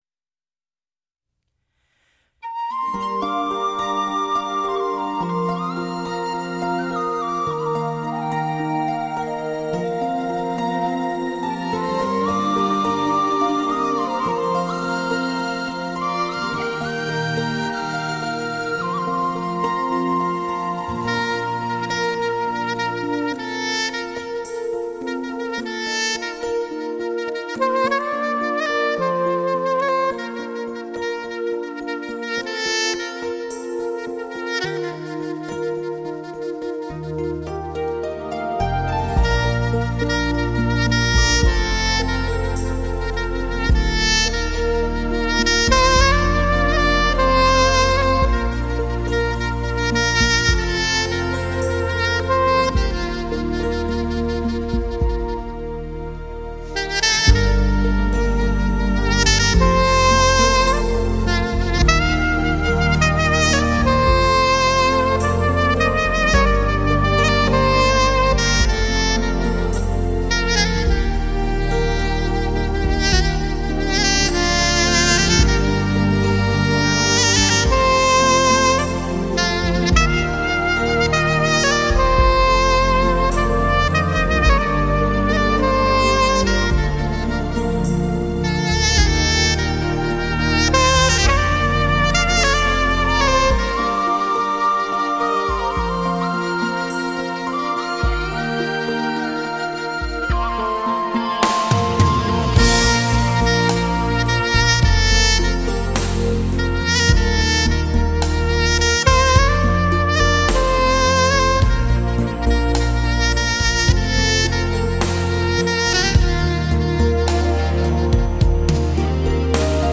曲调有神、缠绵，回味无穷，令人陶醉。